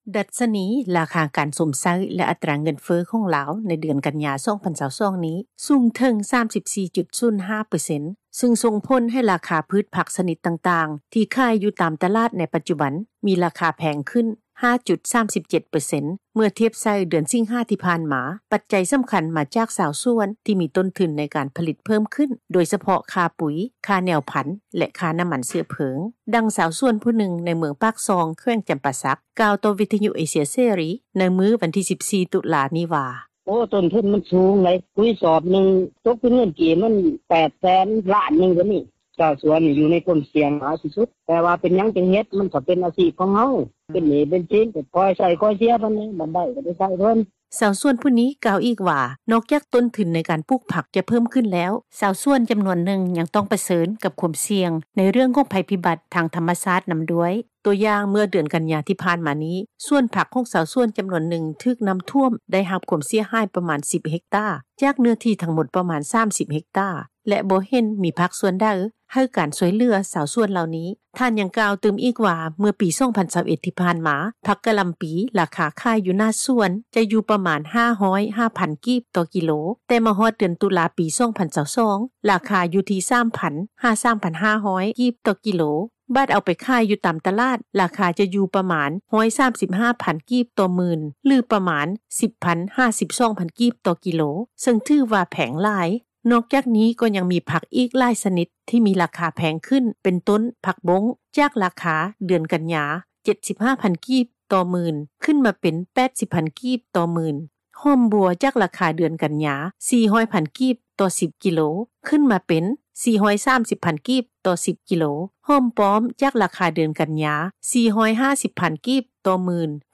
ດັ່ງຊາວສວນ ຜູ້ນຶ່ງໃນເມືອງປາກຊ່ອງ ແຂວງ ຈຳປາສັກ ກ່າວຕໍ່ວິທຍຸ ເອເຊັຽເສຣີ ໃນມື້ວັນທີ່ 14 ຕຸລານີ້ວ່າ:
ດັ່ງຜູ້ປະກອບການ ຮ້ານອາຫານແຫ່ງນຶ່ງ ໃນນະຄອນຫລວງວຽງຈັນ ກ່າວຕໍ່ວິທຍຸ ເອເຊັຽເສຣີ ໃນມື້ດຽວກັນນີ້ວ່າ: